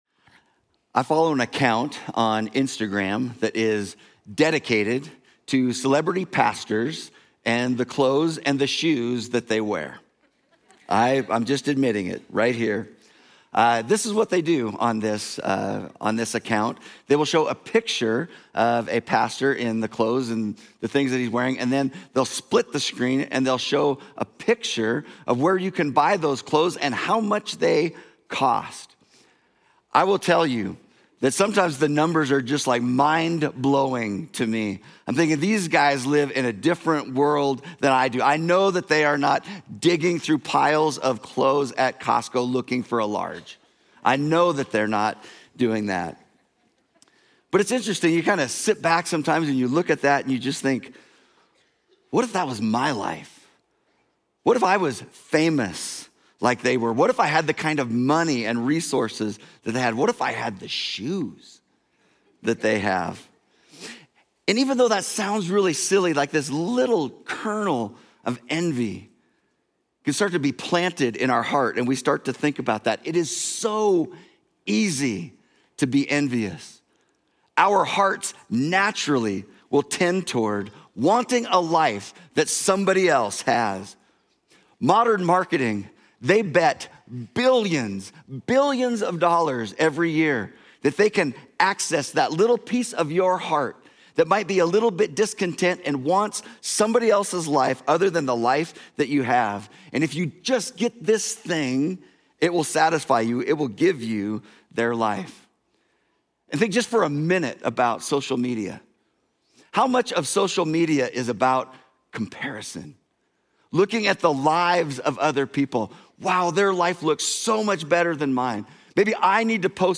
Journey Church Bozeman Sermons